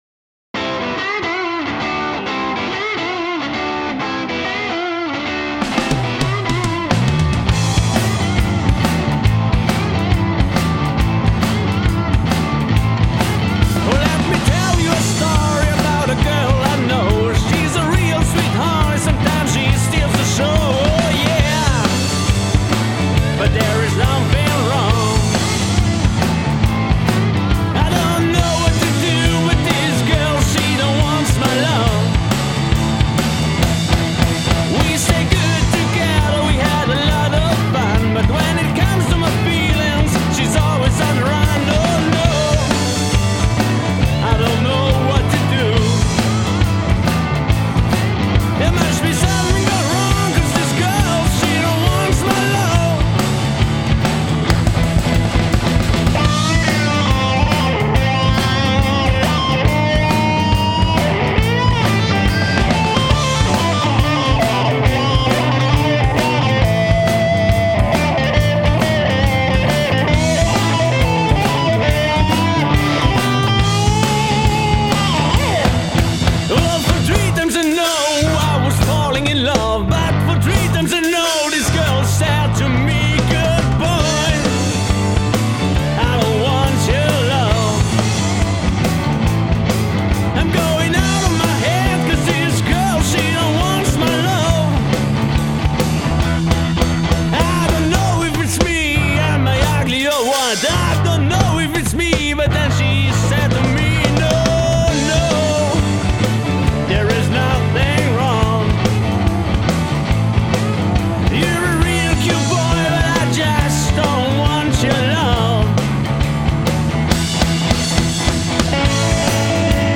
Blues und Rock aus den 70er, 80er und 90er.
vocals/guitar
bass
drums